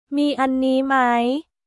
ミーアンニー マイ？